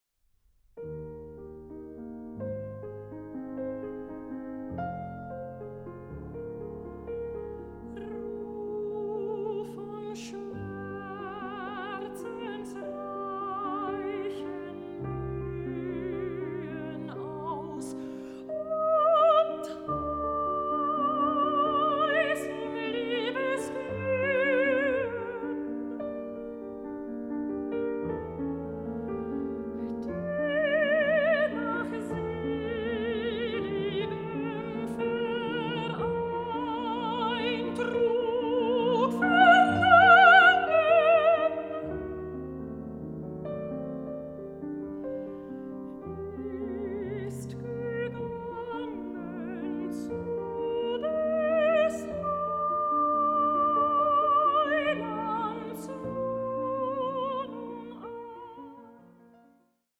GERMAN AND FRENCH SONGS ON LOVE AND LOSS